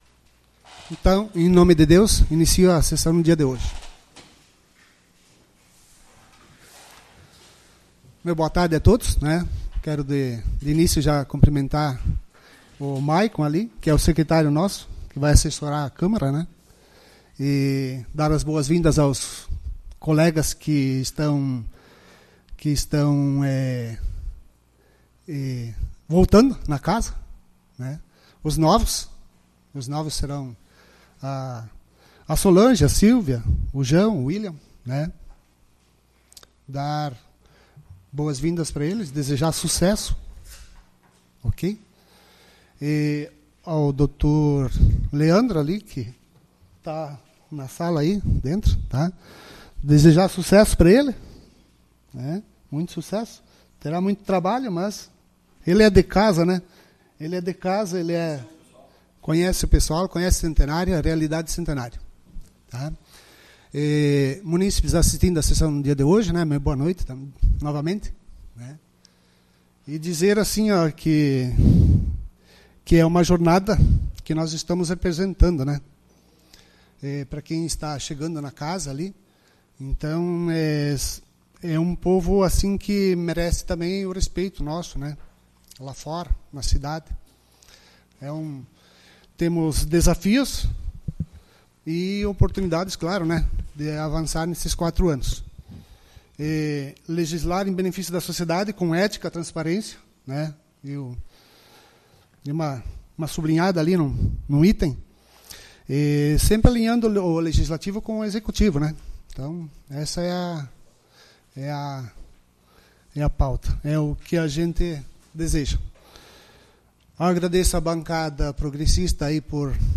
Sessão Extraordinária do dia 06/01/2025.